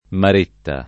maretta [ mar % tta ]